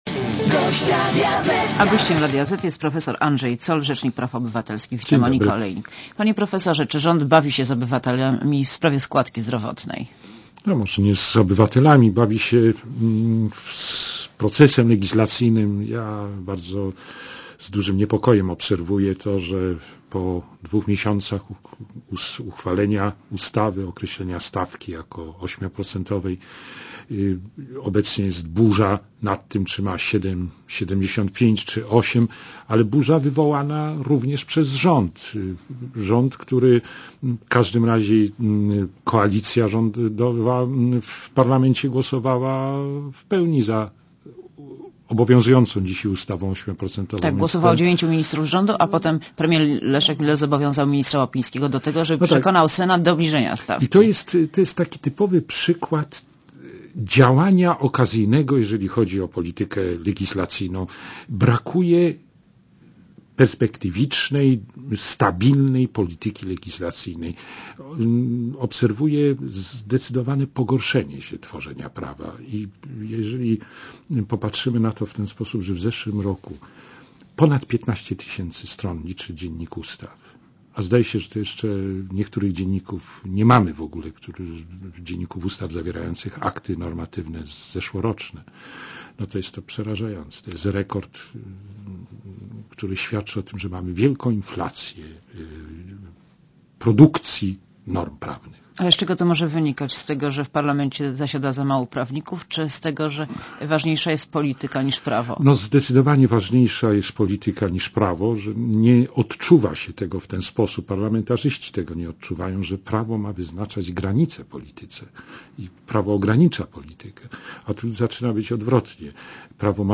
Monika Olejnik rozmawia z Andrzejem Zollem - rzecznikiem praw obywatelskich